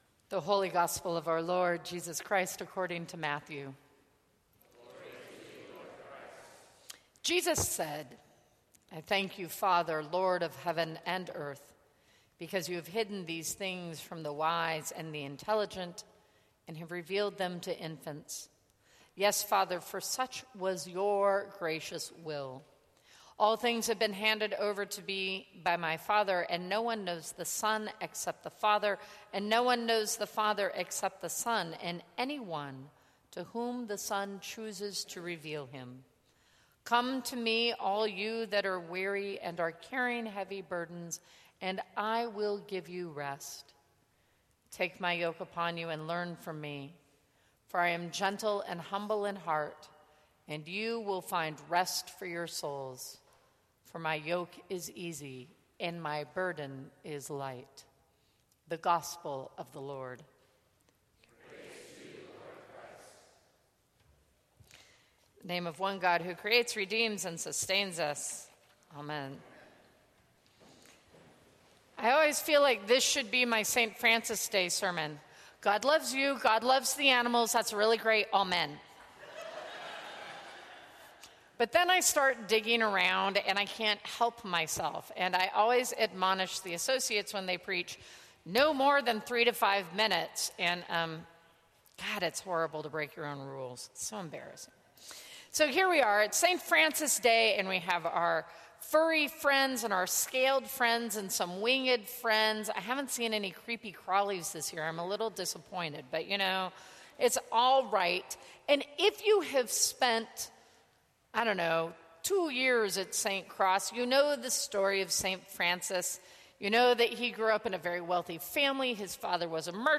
Sermons from St. Cross Episcopal Church Being Vulnerable Oct 16 2015 | 00:10:20 1x 00:00 / 00:10:20 Subscribe Share Apple Podcasts Spotify Overcast RSS Feed Share Link Embed